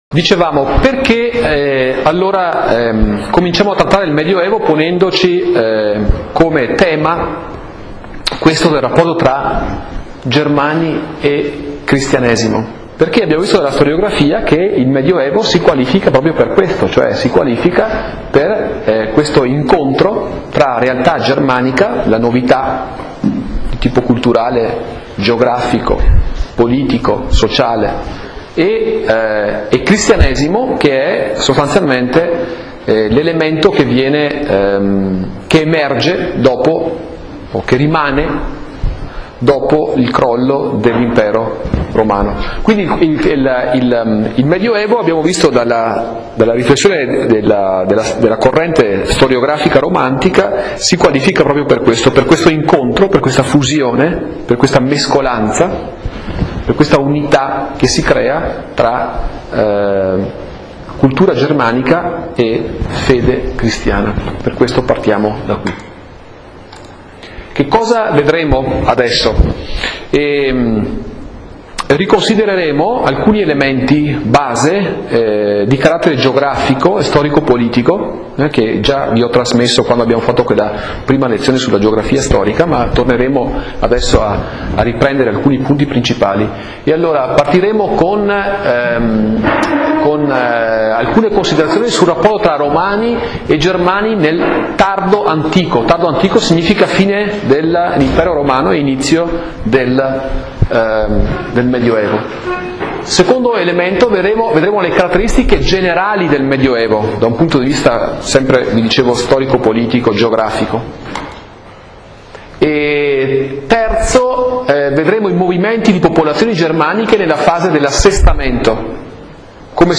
In Italian: 18� Lezione - 3 marzo 2010